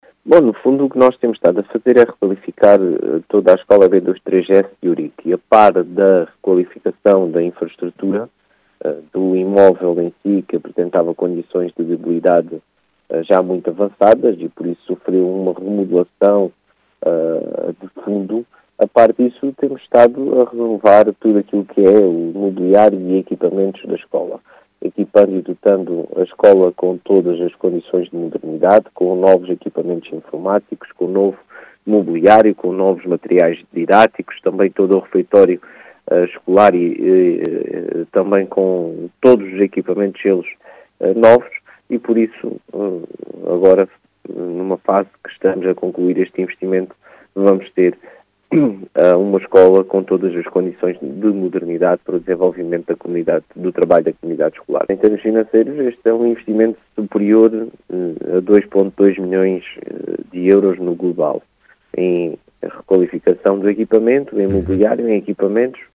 As explicações são de Marcelo Guerreiro, presidente da Câmara Municipal de Ourique, que fala de uma intervenção alargada naquele estabelecimento de ensino, que está orçada em 2,2 milhões de euros.
Marcelo-Guerreiro.mp3